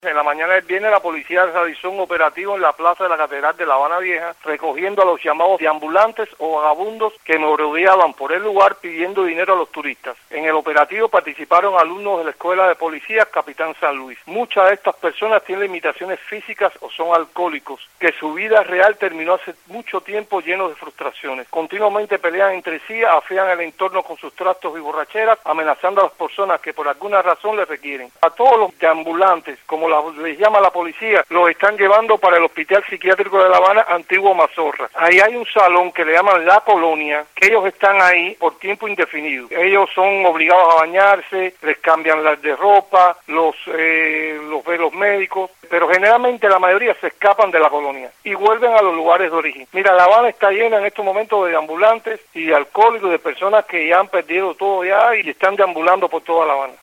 Noticias de Radio Martí
En un operativo policial realizado el viernes en La Habana Vieja, decenas de mendigos fueron llevados a la fuerza al Hospital Psiquiátrico conocido como Mazorra. El periodista independiente